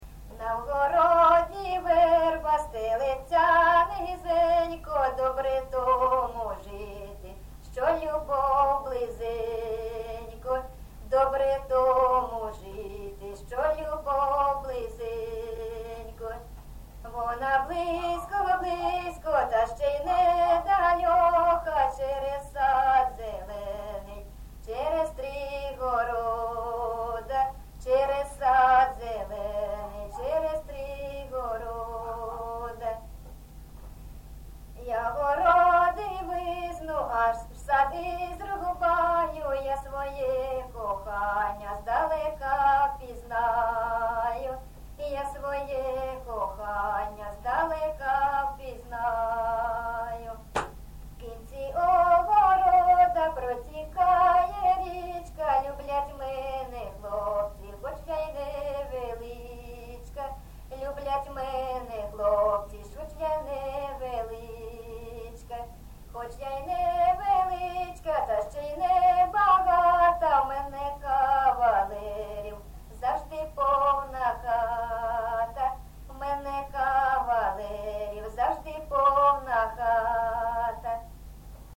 ЖанрПісні з особистого та родинного життя
Місце записус-ще Троїцьке, Сватівський район, Луганська обл., Україна, Слобожанщина